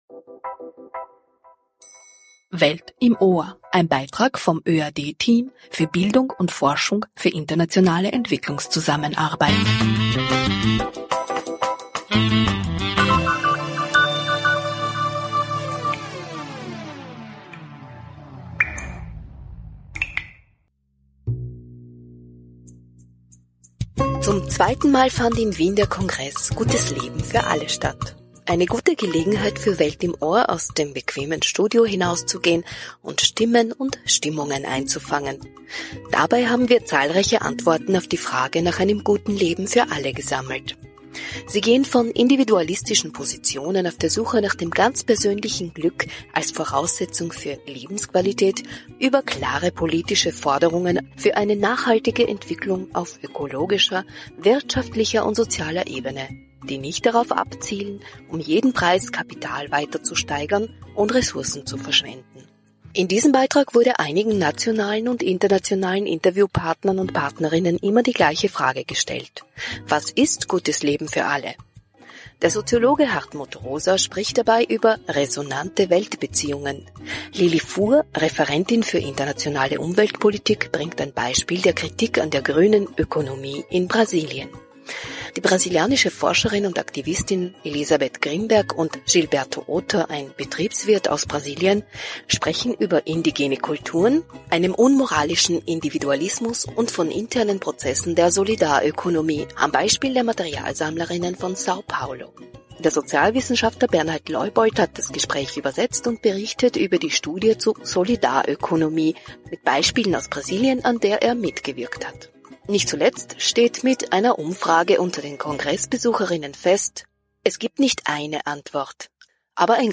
Bericht über einen engagierten Kongress in Wien ~ Welt im Ohr Podcast